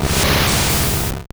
Cri de Kadabra dans Pokémon Or et Argent.